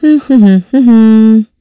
Valance-Vox_Hum.wav